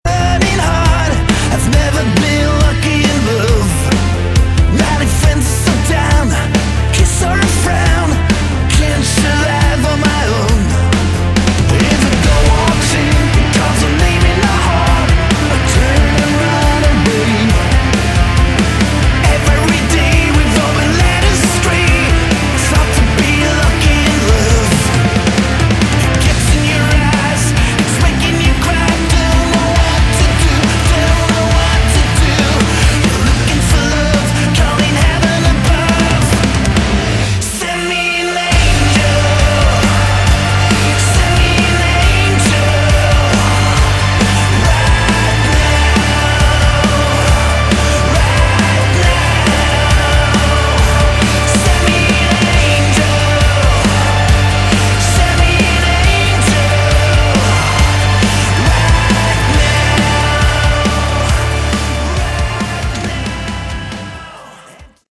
Category: Modern Hard Rock
vocals
bass
keyboards, guitars
drums